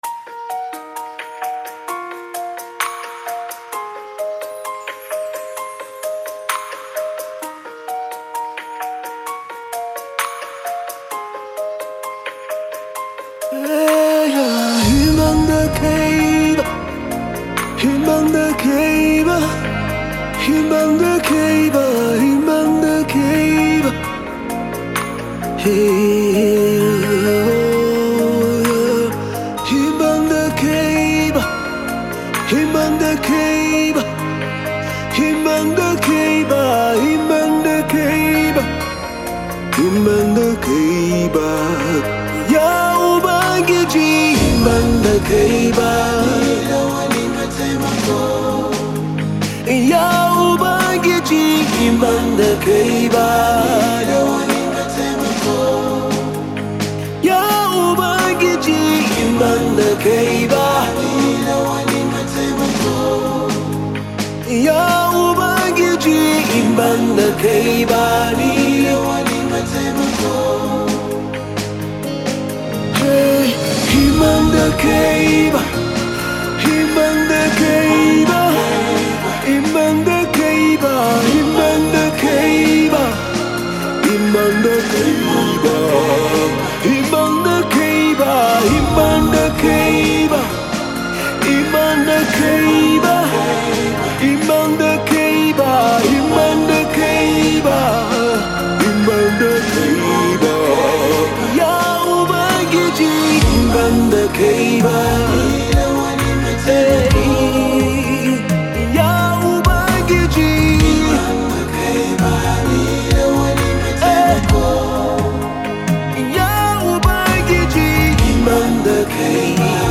gospel
Hausa song